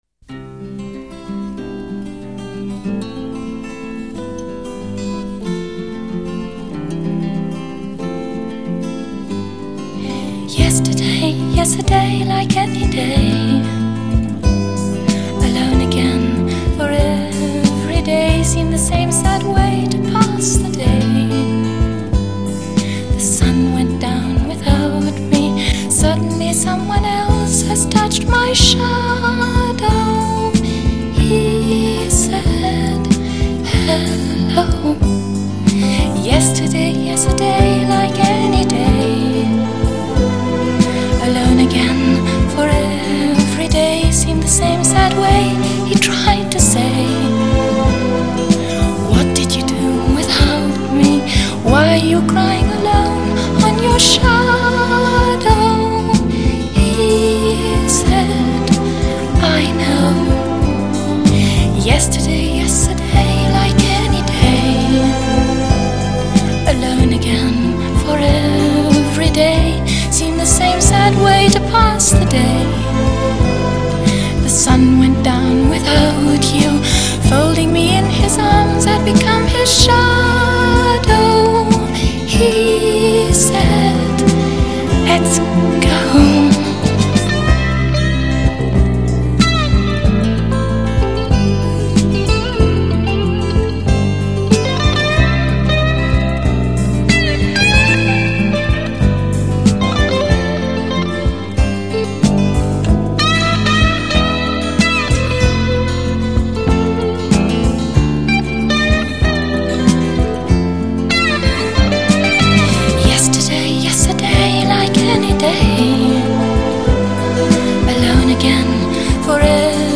她不是什麽优质美音，就是轻轻柔柔的气音
急促的声音把你带回到过去的美好回忆，使你久久地伫立着无法面对即将到来的明天.